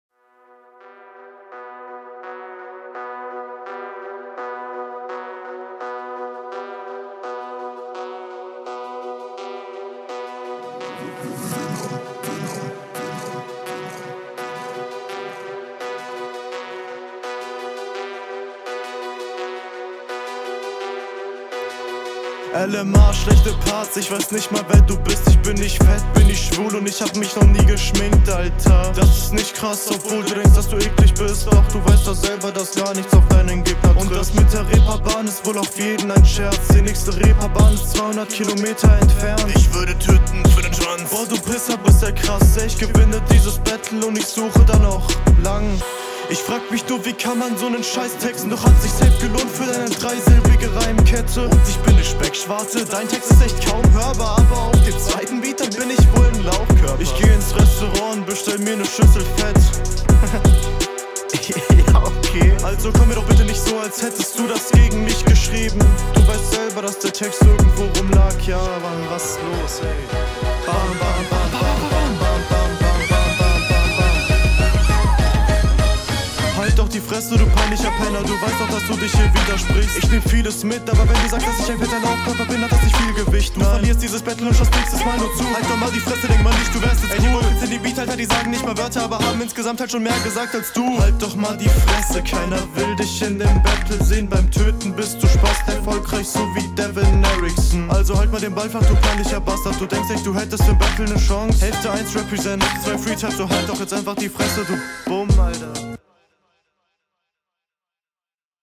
Flow: Find ich erstaunlicherweise etwas enttäuschend, besonders auf dem 2 Beat.